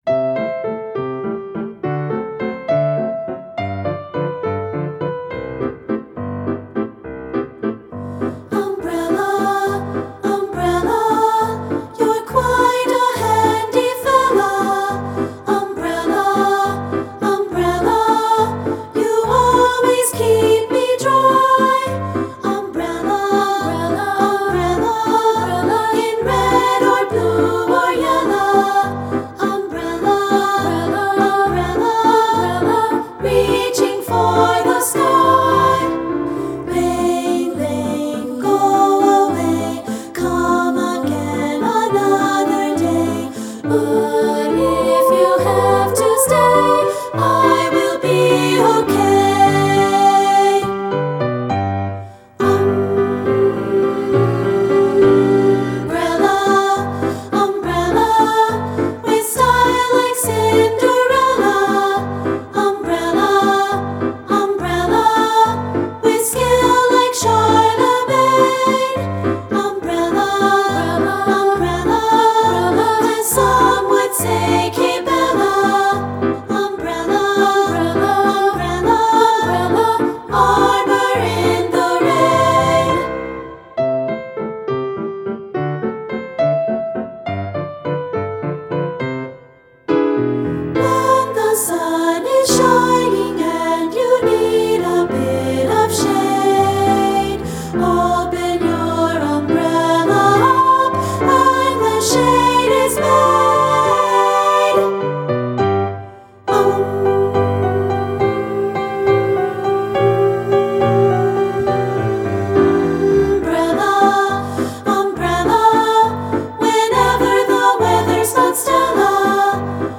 Choral Light Concert/Novelty
lilting waltz tempo